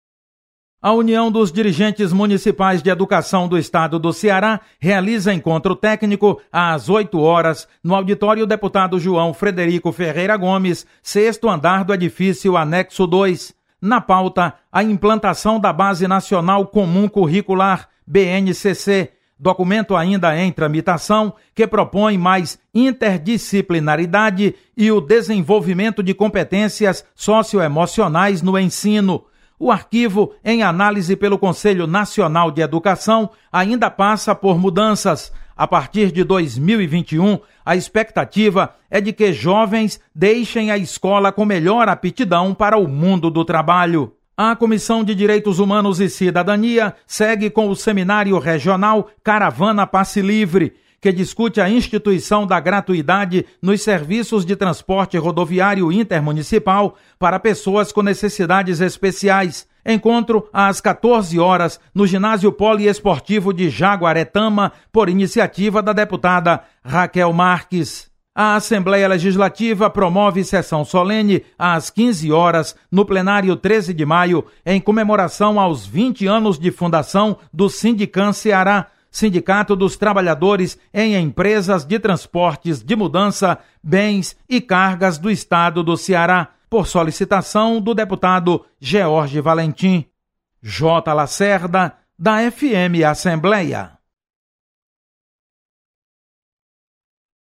Acompanhe as atividades desta segunda-feira (25/06) na Assembleia Legislativa. Repórter